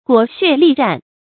裹血力戰 注音： ㄍㄨㄛˇ ㄒㄩㄝˋ ㄌㄧˋ ㄓㄢˋ 讀音讀法： 意思解釋： 猶言浴血奮戰。